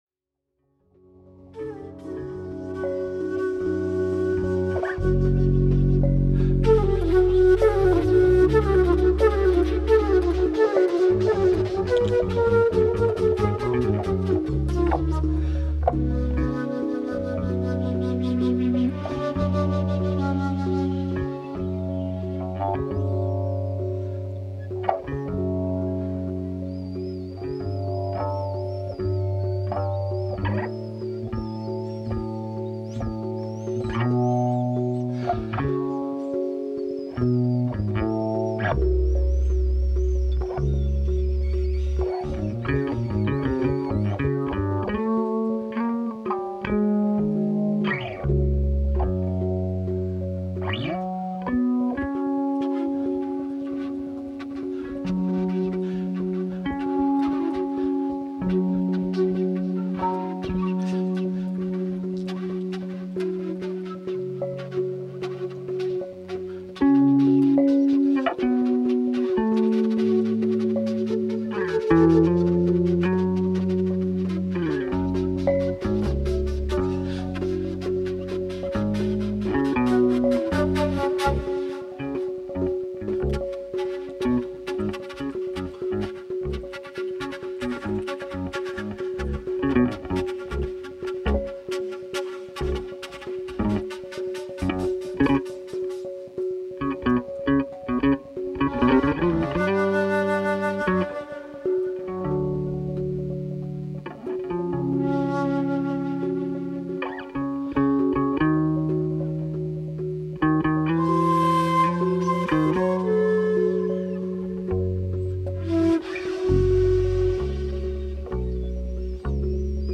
sax and flute
drums and keyboards